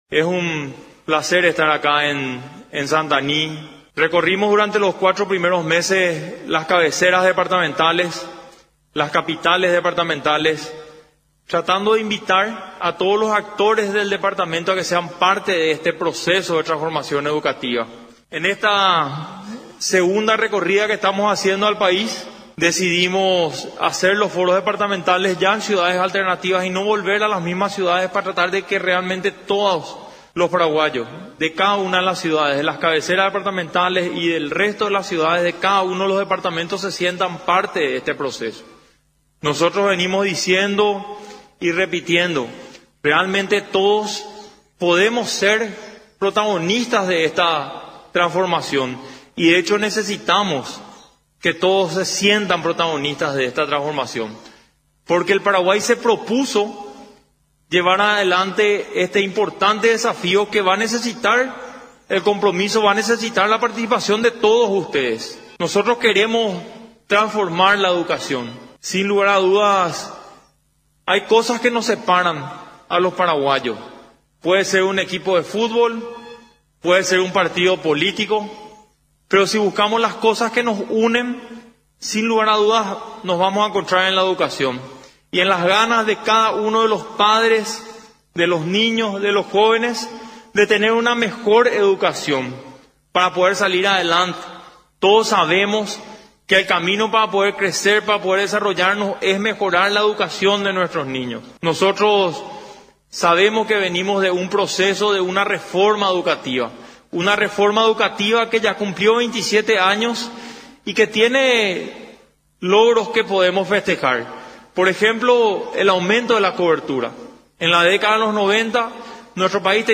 En la ciudad de San Estanislao del departamento de San Pedro, se realizó este jueves el 14° foro departamental, presidido por el titular del Ministerio de Educación y Ciencias (MEC), Juan Manuel Brunetti, la actividad se desarrolló para socializar el Plan Nacional de Transformación Educativa, que apunta a construir una hoja de ruta que defina la educación paraguaya para los próximos años con finalidad diseñar las principales estrategias de transformación educativa, priorizando las realidades de cada zona del país con sus particularidades y recursos.
Durante su intervención en el norte del país, el ministro del MEC, instó a todos los sectores a unir esfuerzos para mejorar la calidad educativa en la región, partiendo de las necesidades departamentales con énfasis en las acciones a llevar adelante en el segundo departamento con 1.128 instituciones educativas.